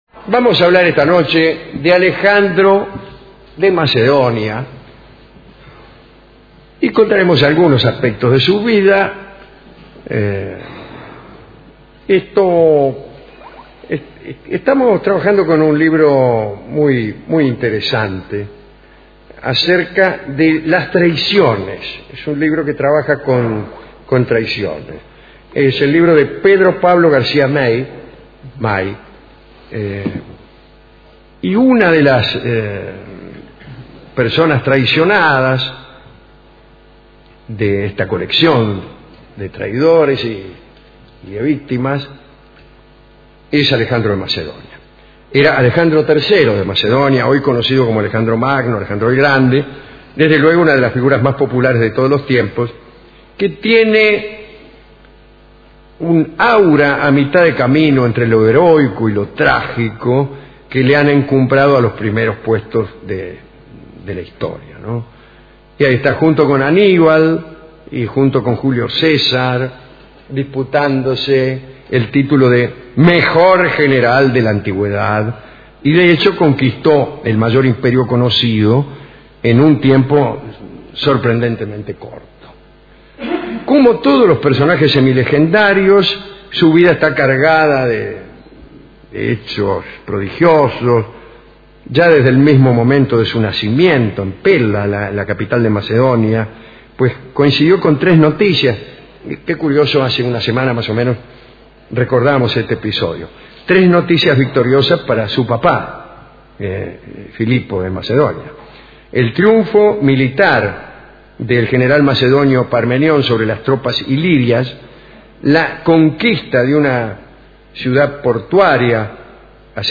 Estudios de Radio Rivadavia (AM 630 kHz), 1989